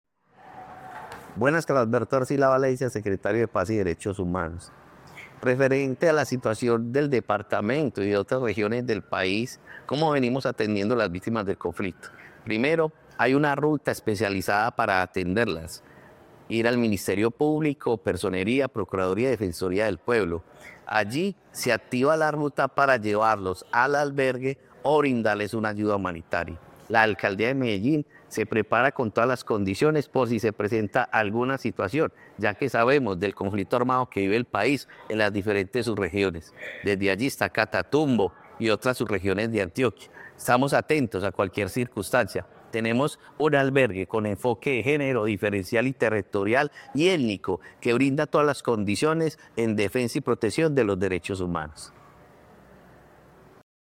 Palabras de Carlos Alberto Arcila Valencia, secretario de Paz y Derechos Humanos La Alcaldía de Medellín, a través de la Secretaría de Paz y Derechos Humanos, intensifica sus esfuerzos para asistir a 25 familias desplazadas por el conflicto en la región del Catatumbo.